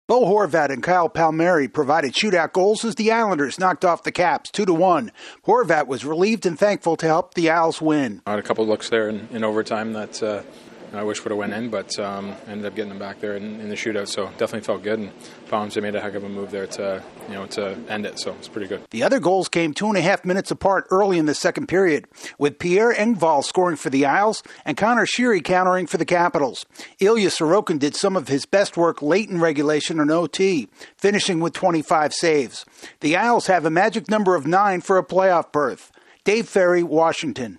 The Islanders keep their six-point cushion in the playoff chase. AP correspondent